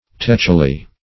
techily - definition of techily - synonyms, pronunciation, spelling from Free Dictionary Search Result for " techily" : The Collaborative International Dictionary of English v.0.48: Techily \Tech"i*ly\, adv.